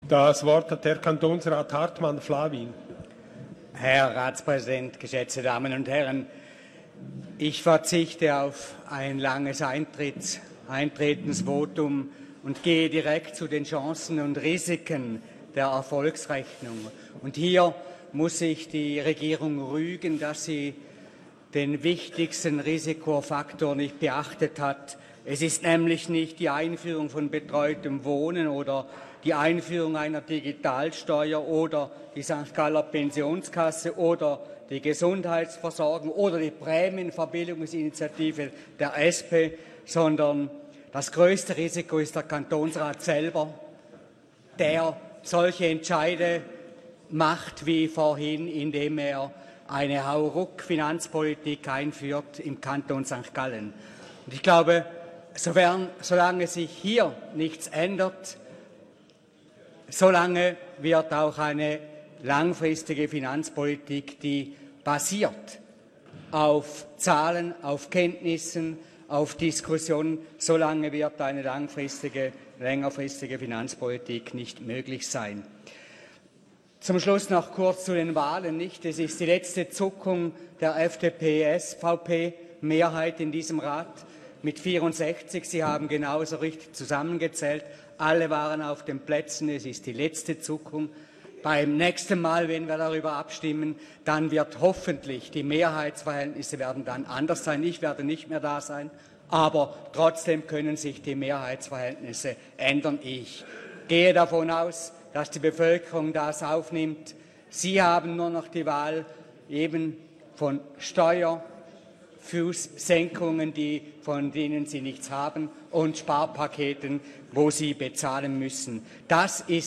Session des Kantonsrates vom 17. bis 19. Februar 2020